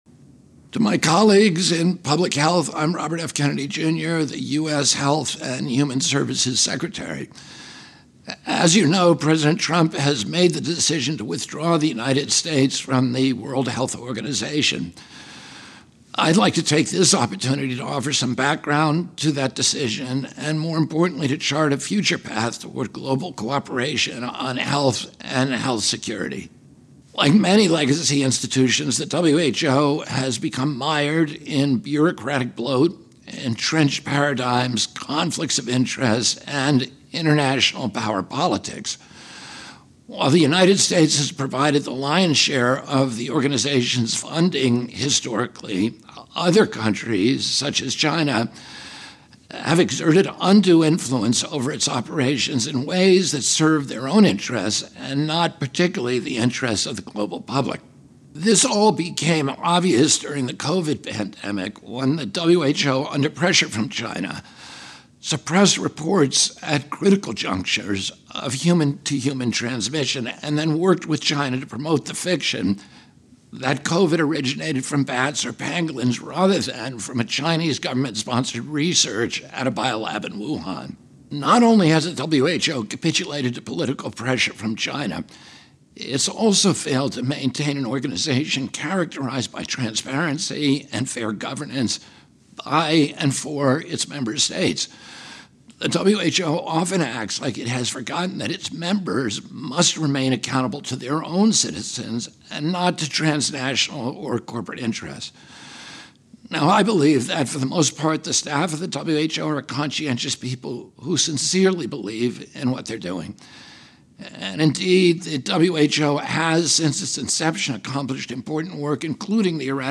Recorded Message to the World Health Assembly
Audio Note: AR-XE = American Rhetoric Extreme Enhancement